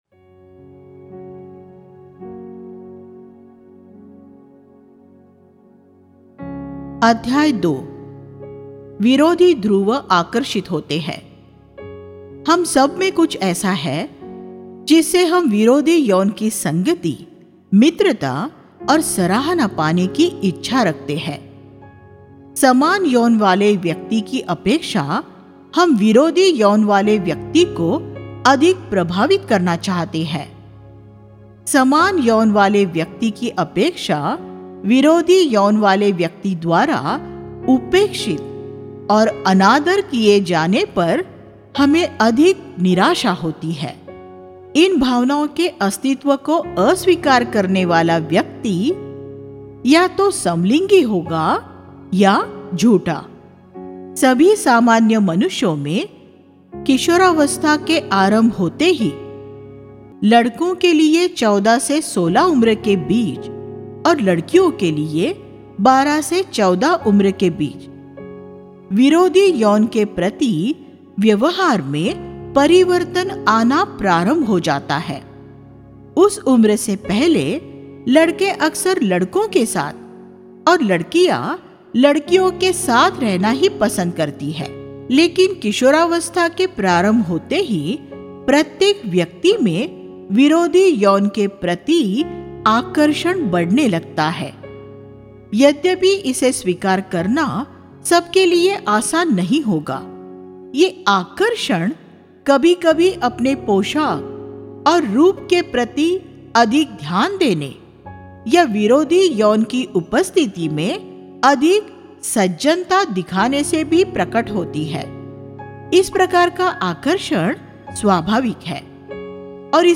Opposite Poles Attract Sex, Love & Marriage Click here to View All Sermons इस शृंखला के उपदेश 1.